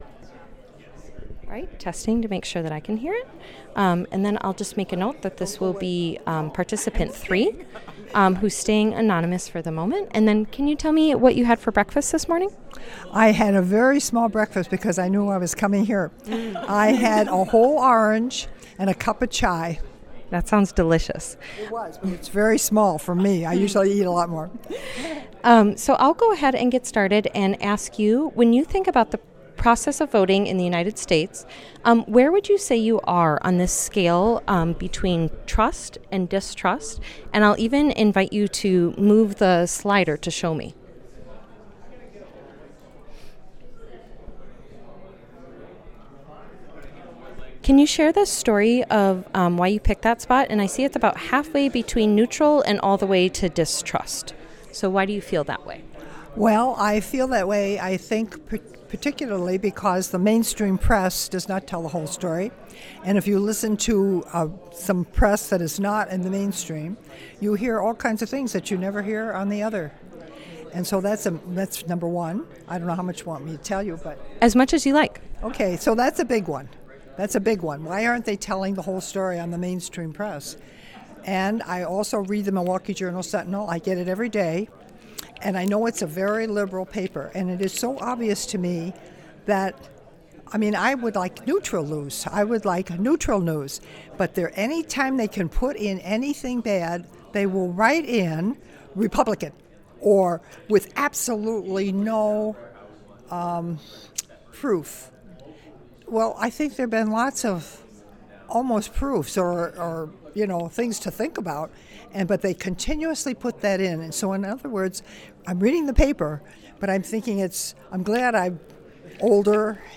Anonymous Participant Interview
Location UWM at Waukesha